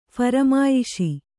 ♪ pharamāyiṣi